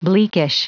Prononciation du mot bleakish en anglais (fichier audio)
Prononciation du mot : bleakish